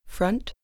front_test_ambiX.wav